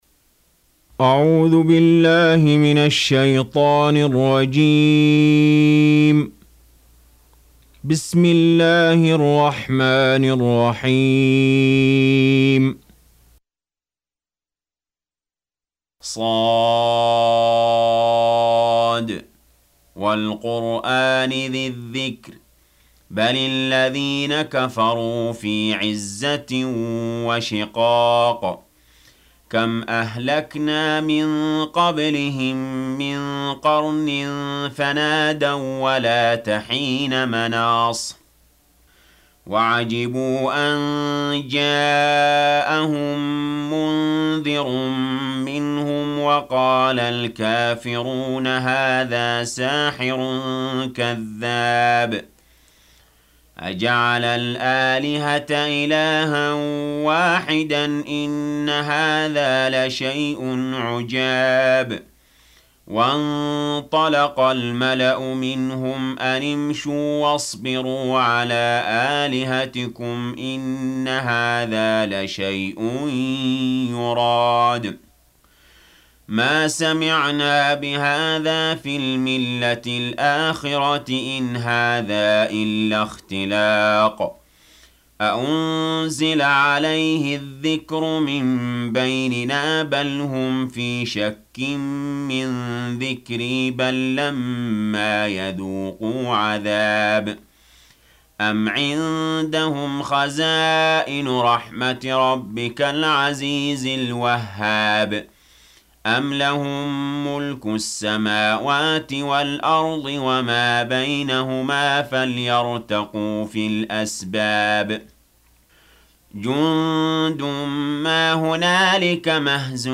Surah Sequence تتابع السورة Download Surah حمّل السورة Reciting Murattalah Audio for 38. Surah S�d. سورة ص N.B *Surah Includes Al-Basmalah Reciters Sequents تتابع التلاوات Reciters Repeats تكرار التلاوات